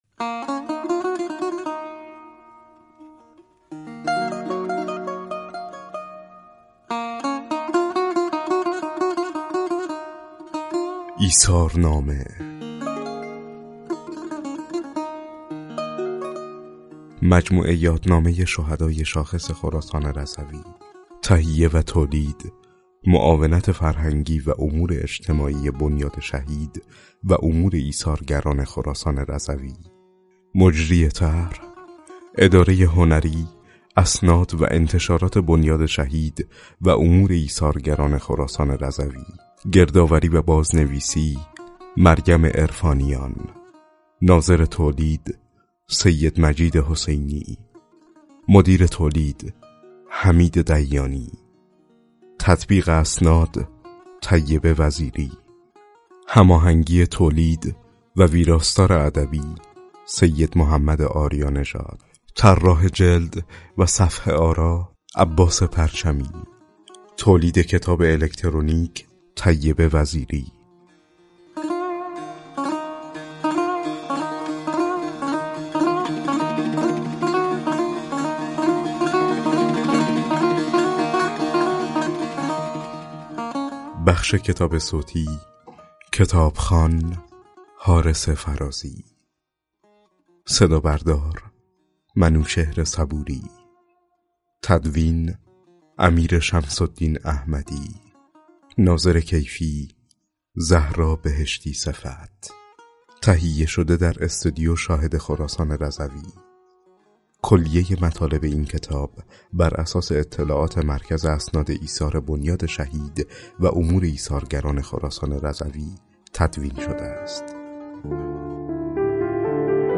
بر این اساس کتاب‌های جیبی، الکترونیکی، و کتاب صوتی 72 تن از شهیدان شاخص استان از میان شهیدان انقلاب اسلامی، ترور، دفاع مقدس، مرزبانی، دیپلمات و مدافع حرم منتشر و رونمایی شده است.